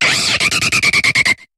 Cri de Bruyverne dans Pokémon HOME.